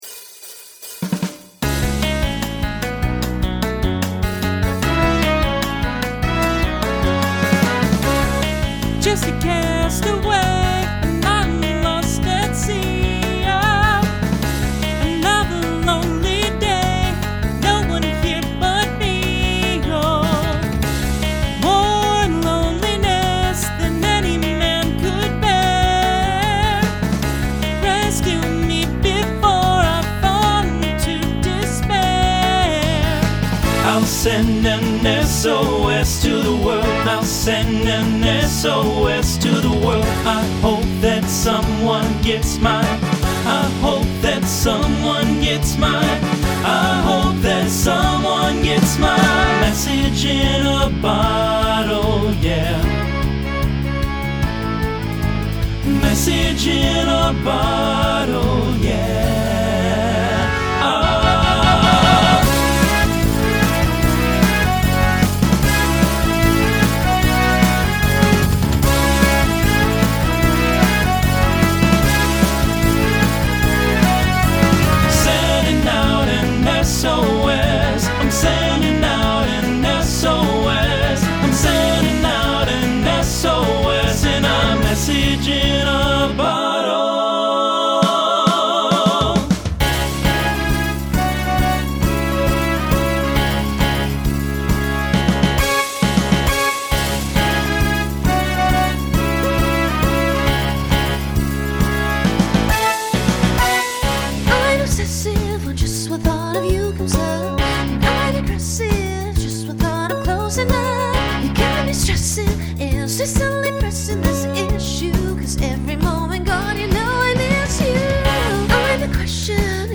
2000s Genre Pop/Dance , Rock
Transition Voicing Mixed